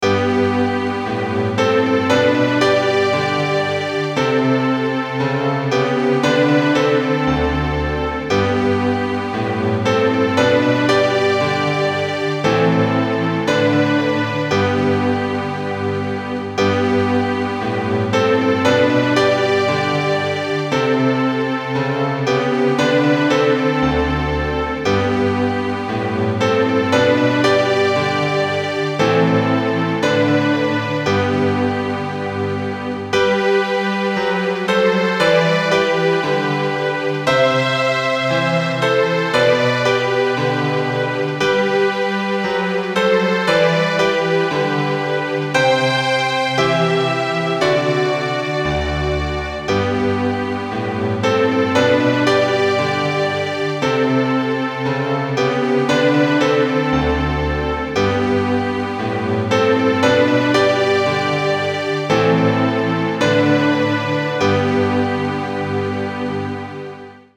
ピアノの鍵盤から流れる旋律は、まるで静かな夜空に輝く星々のように輝きます。